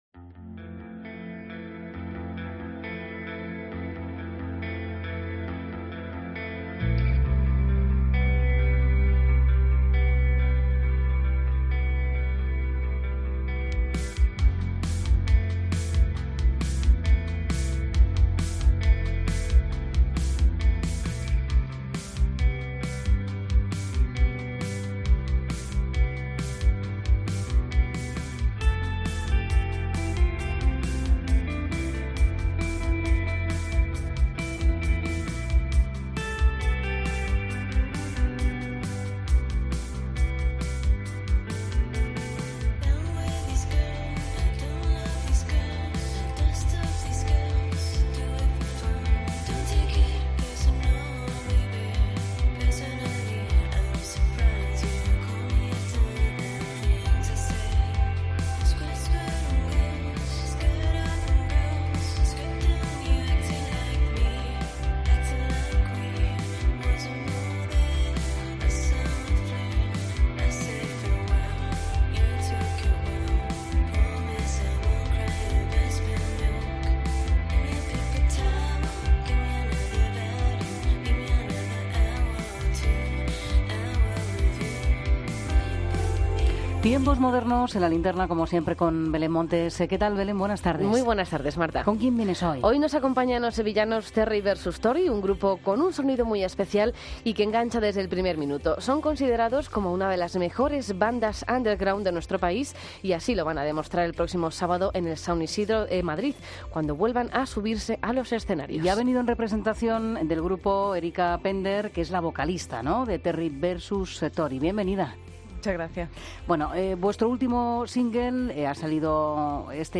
Entrevista a Terry vs Tori en La Linterna